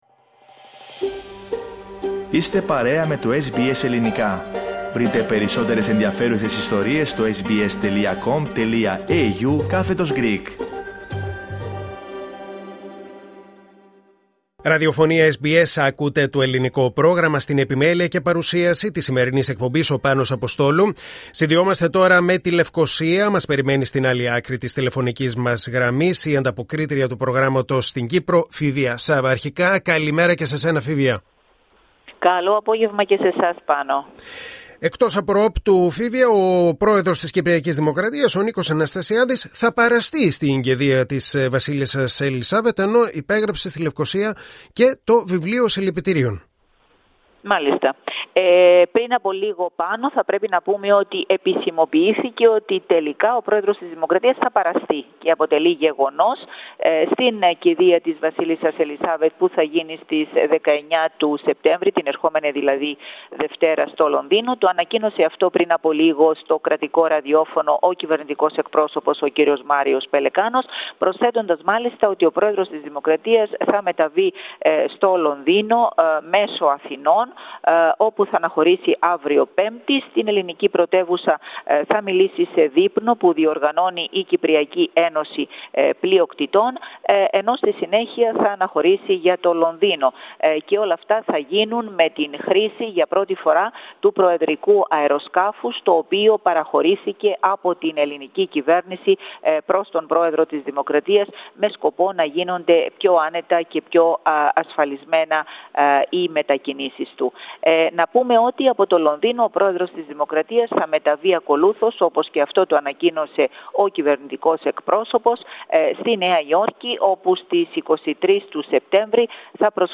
Ακούστε ολόκληρη την ανταπόκριση από την Κύπρο, πατώντας play.